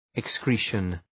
Προφορά
{ık’skri:ʃən}